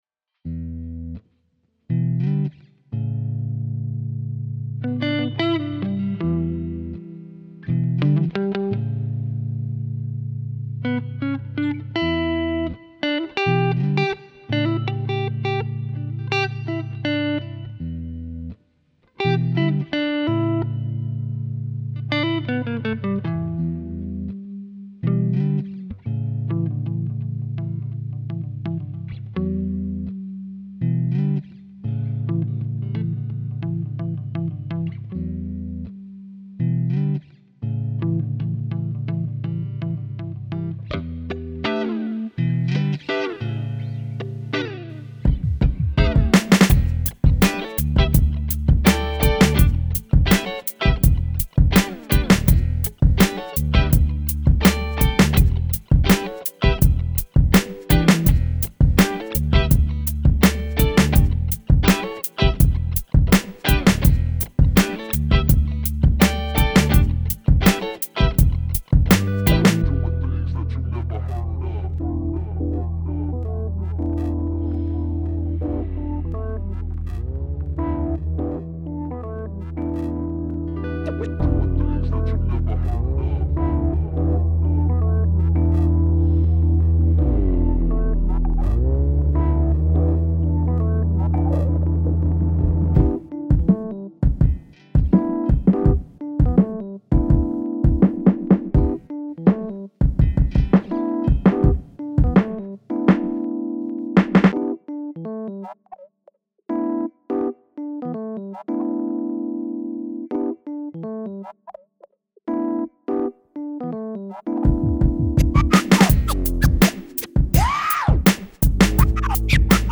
Boring yet groovy instrumental Hip Hop.